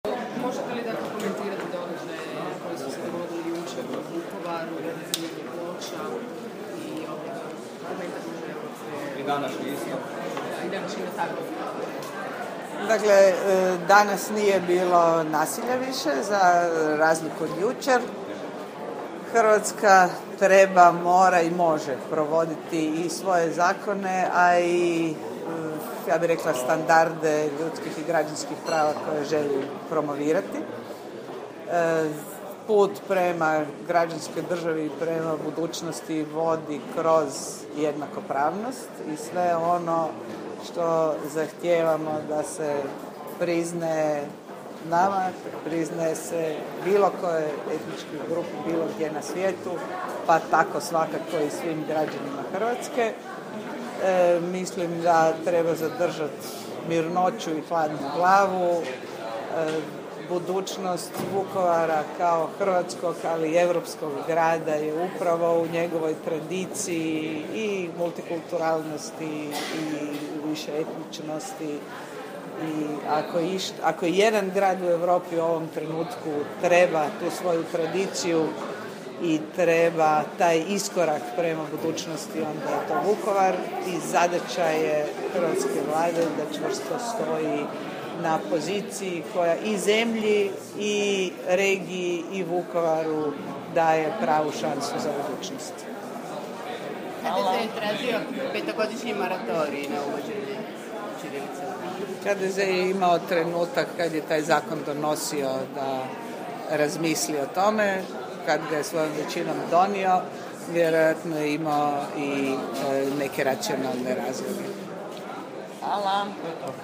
Izjava ministrice Vesne Pusić o događajima u Vukovaru: